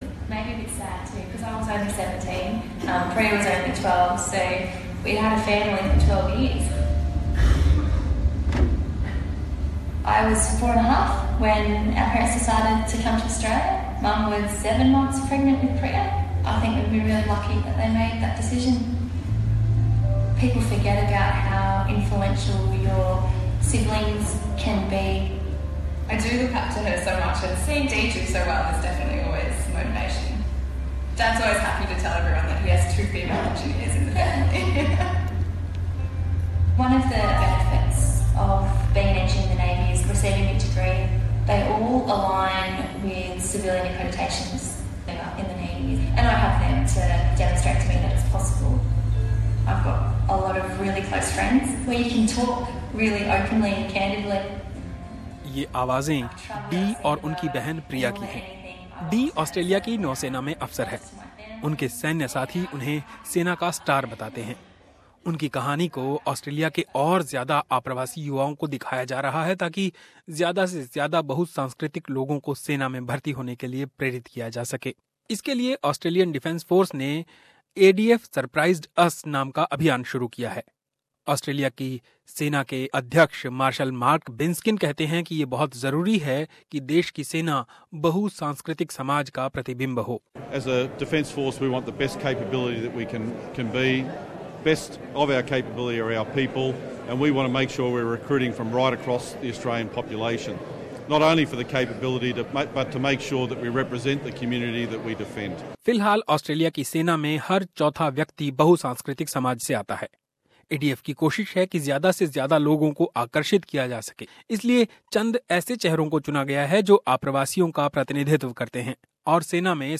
पेश है एक रिपोर्ट...